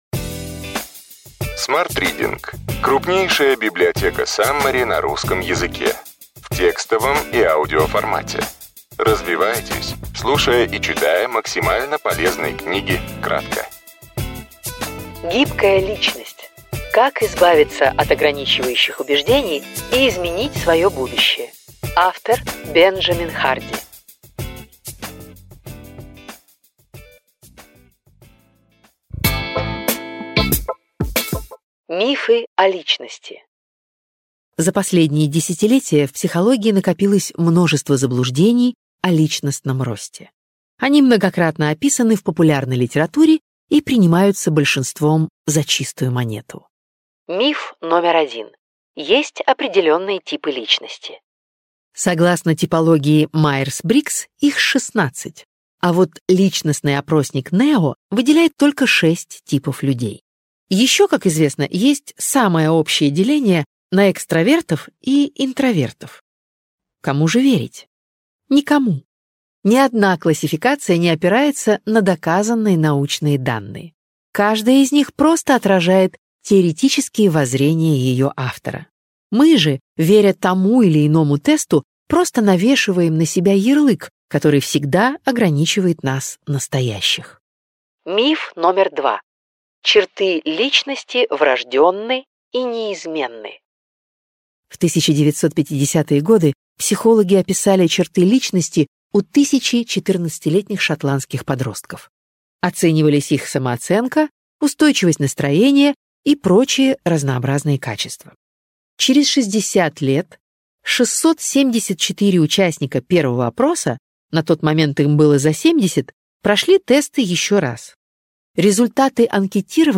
Аудиокнига Ключевые идеи книги: Гибкая личность. Как избавиться от ограничивающих убеждений и изменить свое будущее.